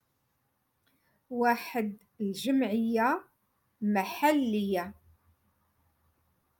Moroccan Dialect- Rotation Three- Lesson Eight